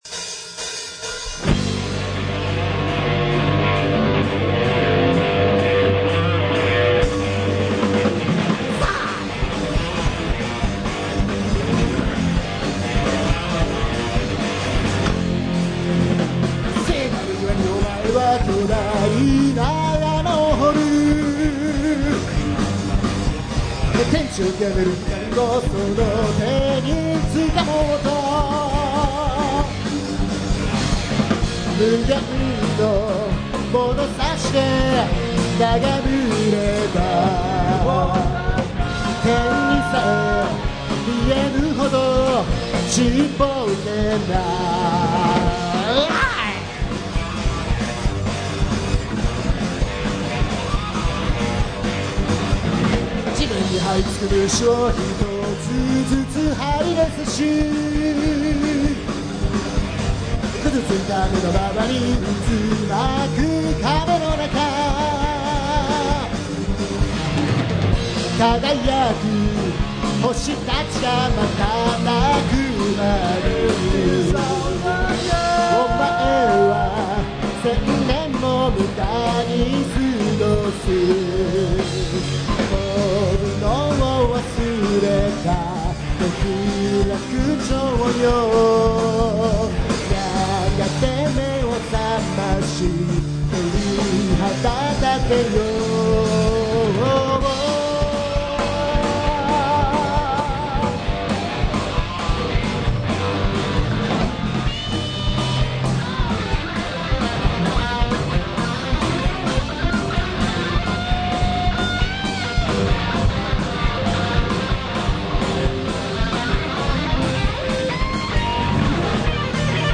at.大塚 Welcome back!!